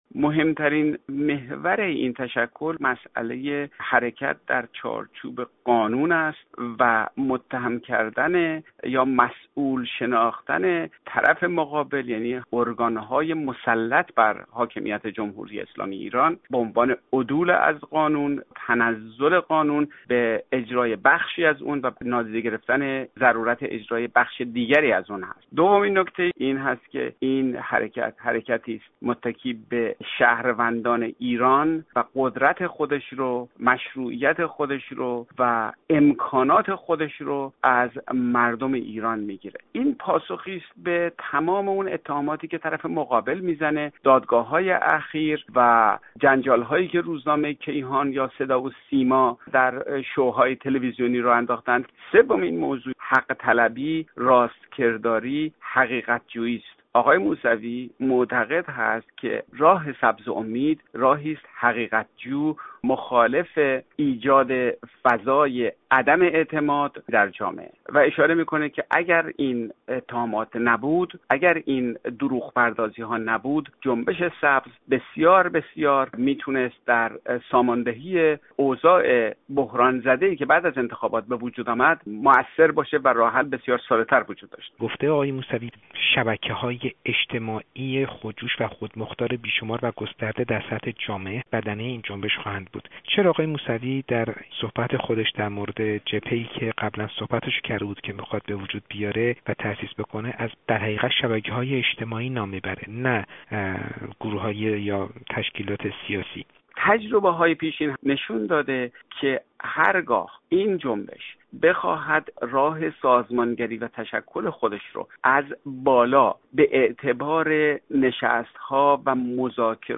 گفت‌وگو با فرخ نگهدار، تحلیلگر سیاسی